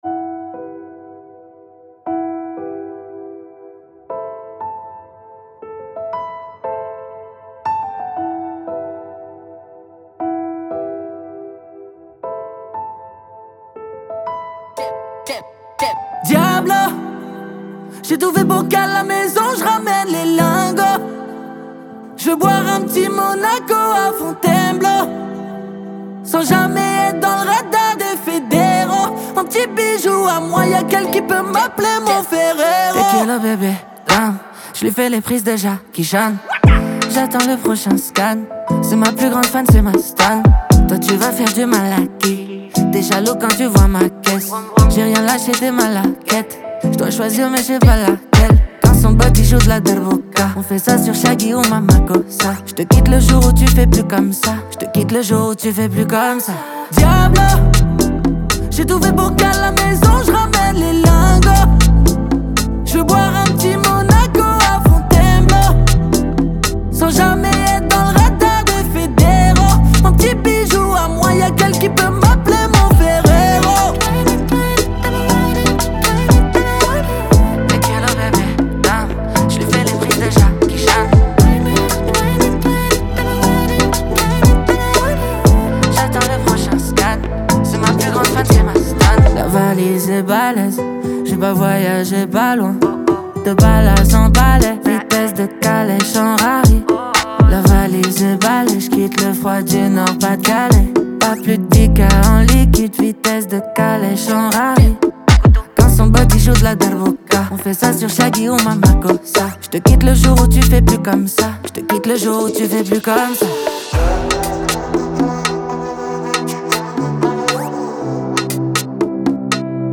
Genre: Country